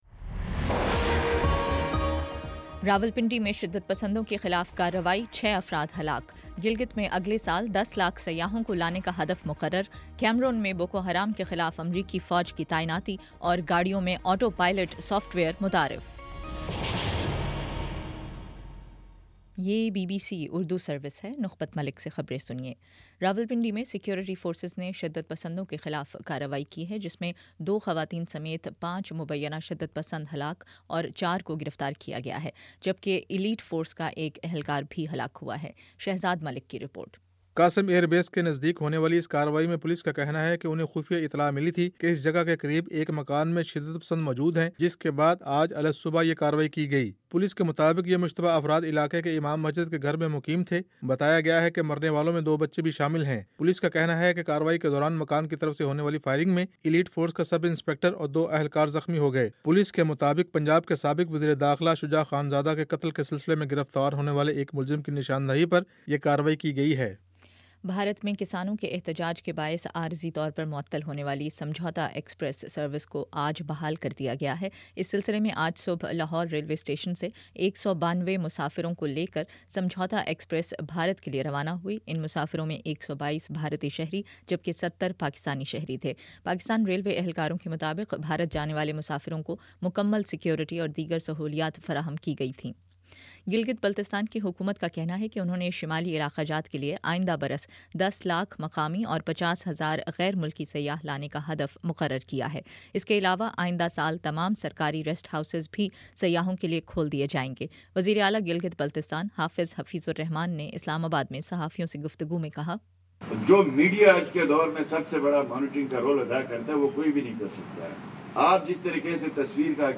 اکتوبر 15 : شام پانچ بجے کا نیوز بُلیٹن